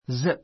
zíp ズィ プ